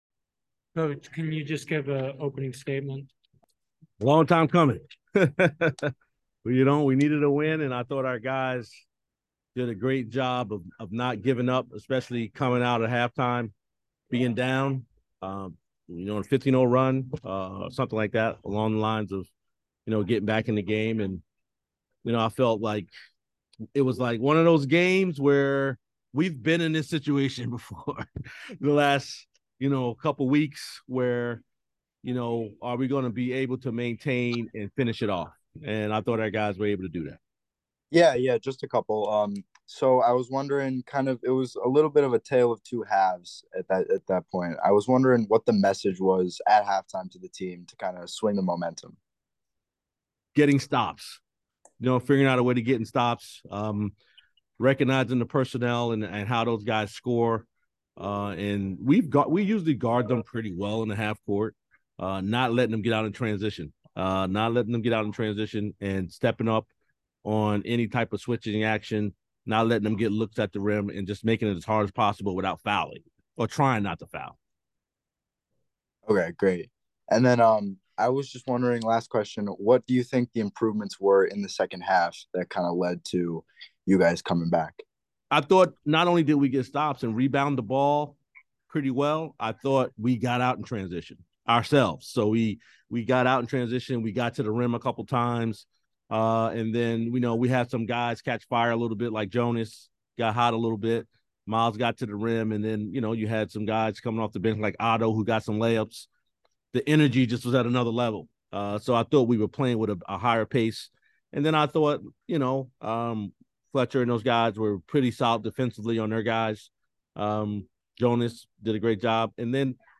Loyola Maryland Postgame Interview